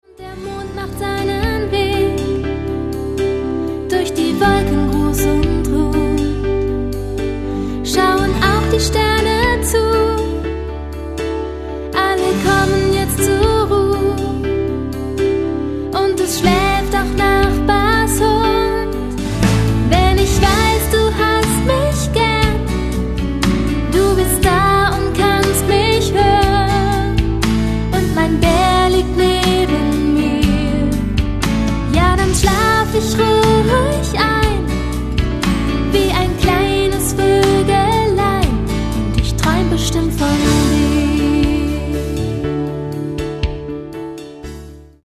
Die schönsten Gute-Nacht-Lieder
• Sachgebiet: Kinderlieder